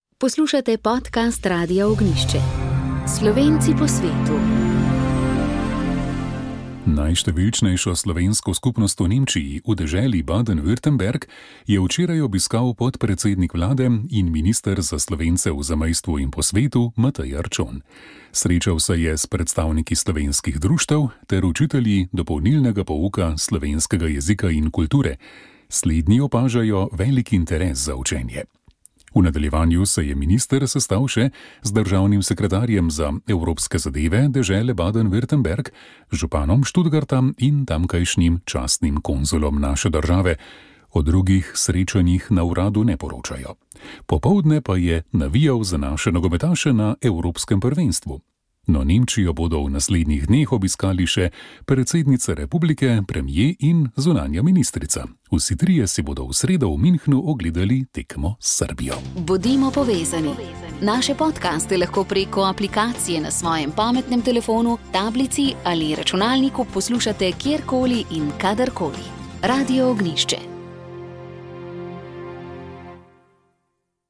Državna sekretarka na Uradu za Slovence v zamejstvu in po svetu Vesna Humar je za naš radio poudarila, da urad želi mednarodno javnost opozoriti na čezmejnost slovenskega kulturnega prostora.